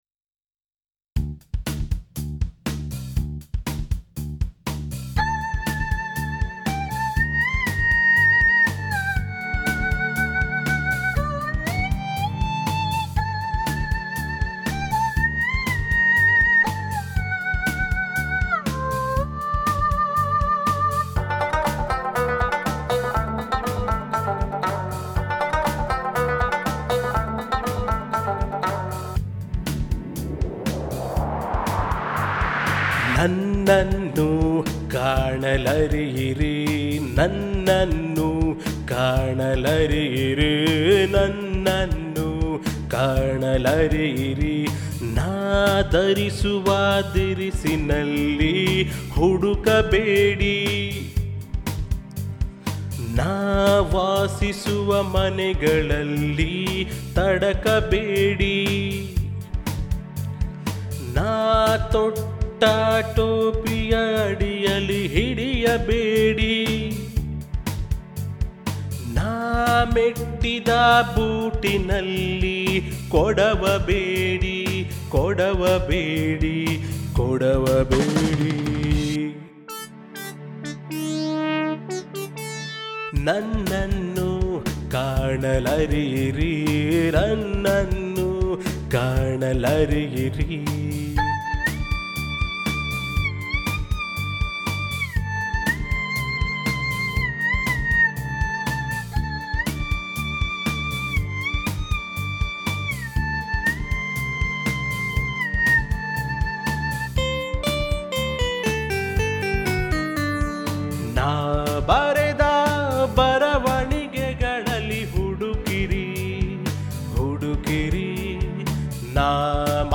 ವಿಶೇಷವೆಂದರೆ ಇತ್ತೀಚೆಗಷ್ಟೇ ಈ ಕವಿತೆ ರಾಗ ಸಂಯೋಜನೆಗೊಂಡು ಸಾಹಿತ್ಯ-ಸಂಗೀತ ಪ್ರಿಯರ ಮನಸೂರೆಗೊಂಡಿದೆ.